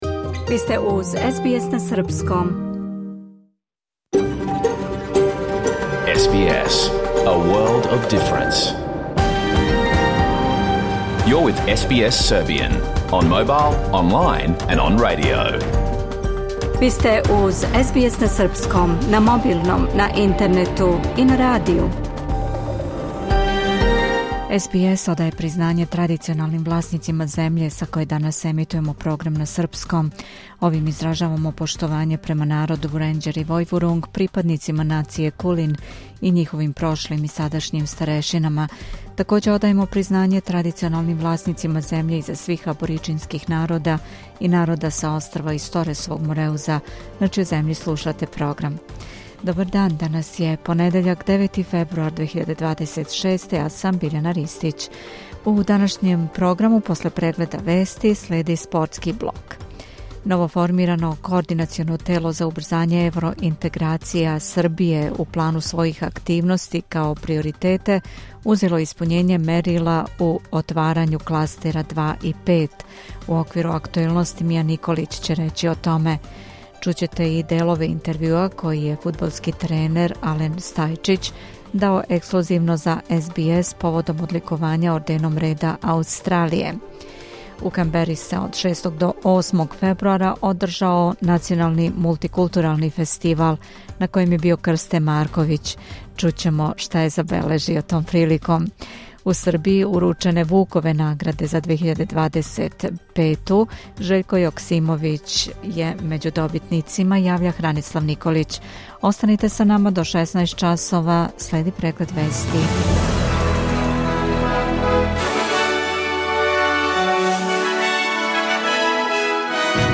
Програм емитован уживо 9. фебруара 2026. године